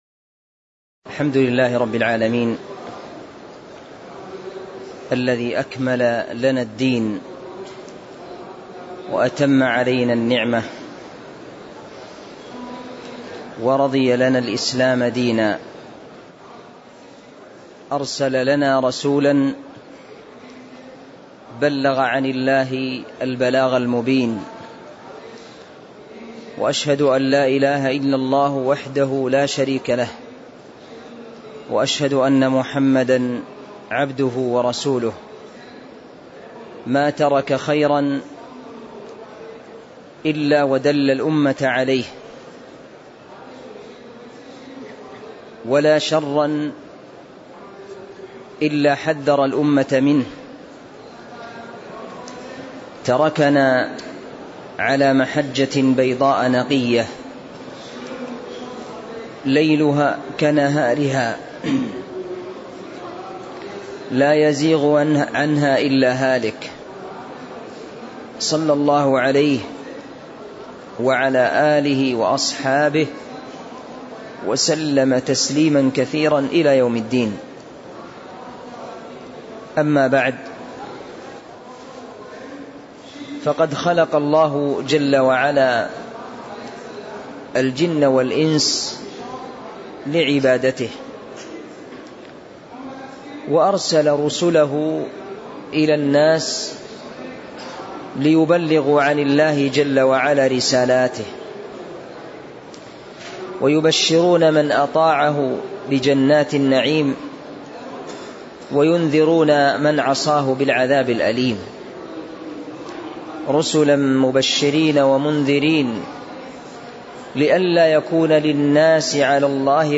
تاريخ النشر ٢٢ رجب ١٤٤٤ هـ المكان: المسجد النبوي الشيخ